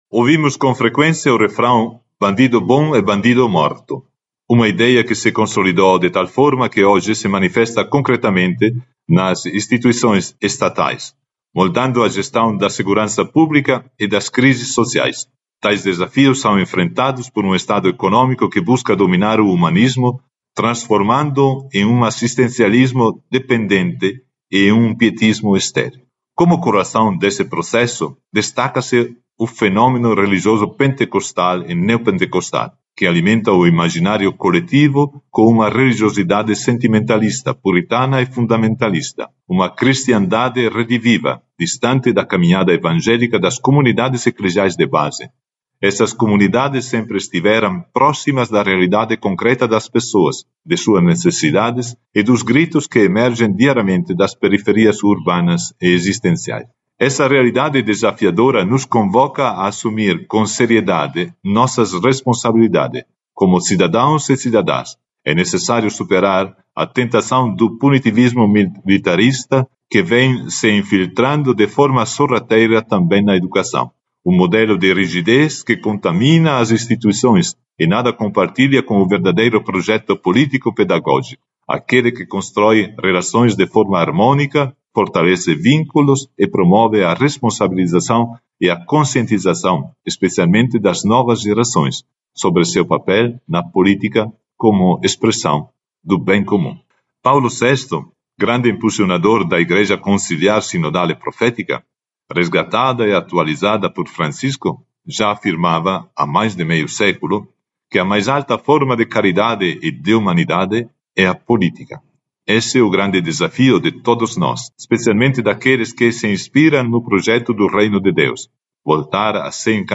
EDITORIAL-3.mp3